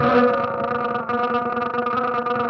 9 Boiling In Dust Guitar Noise.wav